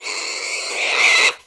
client / bin / pack / Sound / sound / monster / wild_boar / attack_1.wav